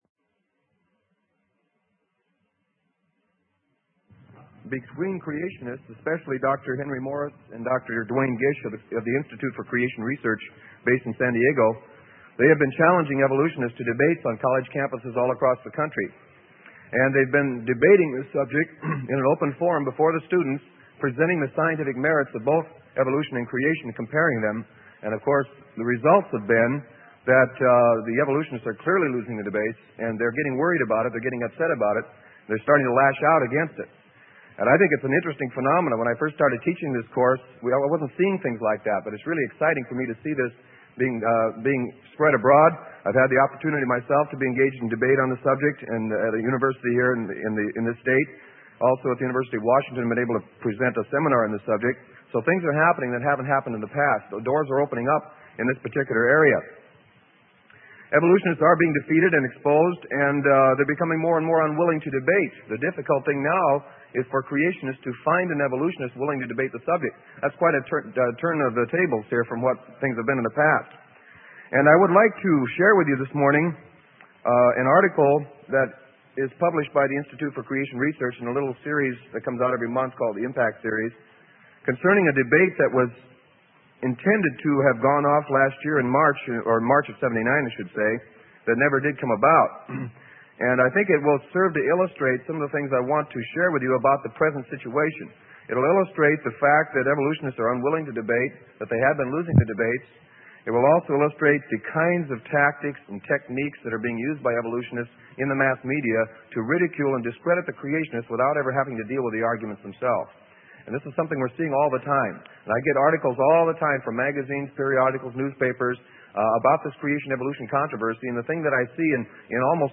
Sermon: Evolution Refutation - Part 4, Update on the Creation/Evolution Controversy - Freely Given Online Library